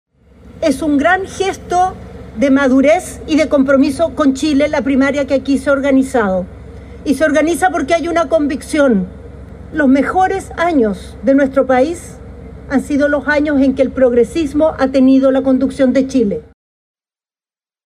La inscripción se concretó el pasado miércoles, en el Servicio Electoral (Servel), donde se realizó un acto cuyo objetivo principal fue dar una señal de unidad en el sector.